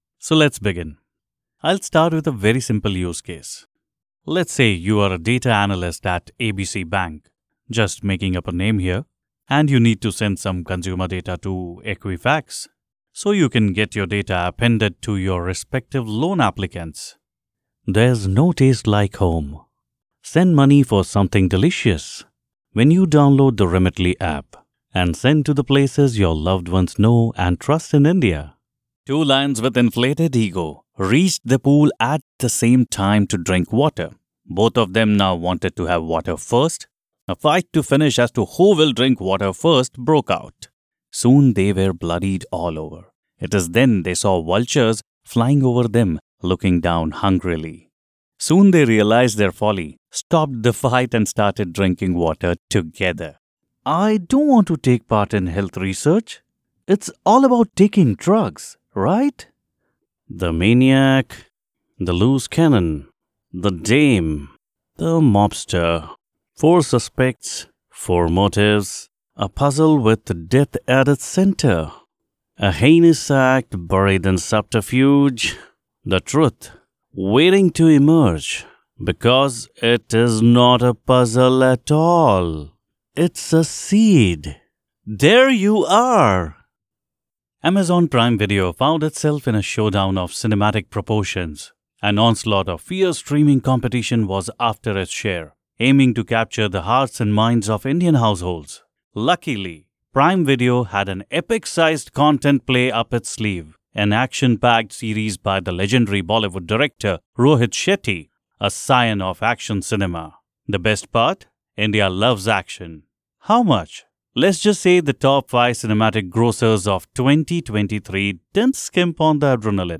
Male
Approachable, Bright, Cool, Corporate, Friendly, Natural, Smooth, Soft, Warm
Microphone: Electro Voice RE20